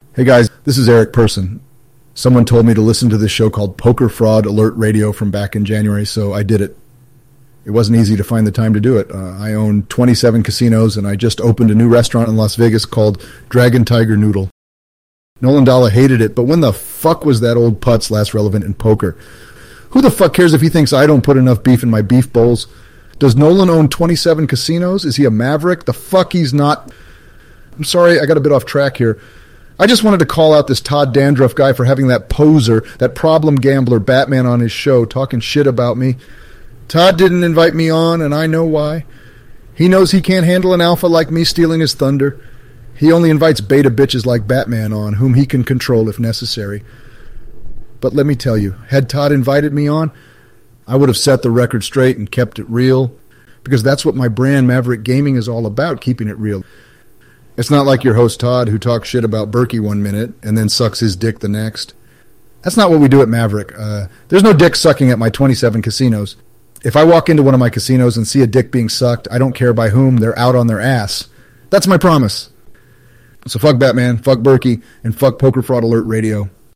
AI version